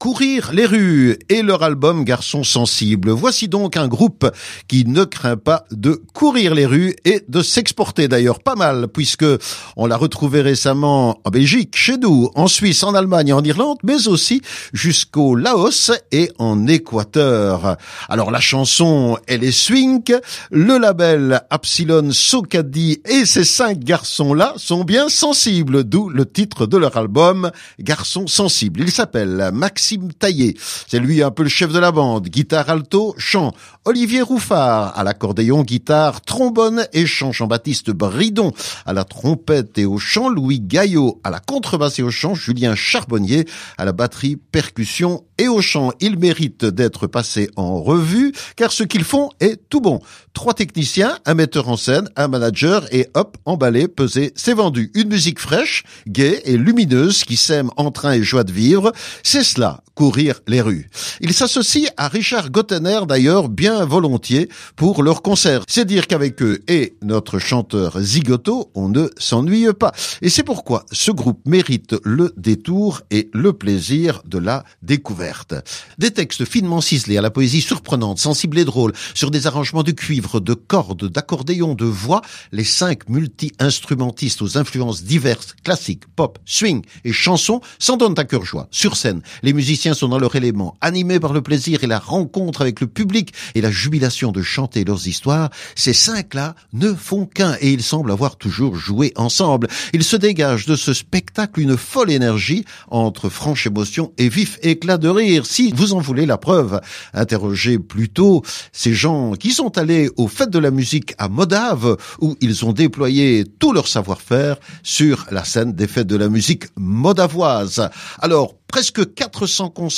batterie
percussions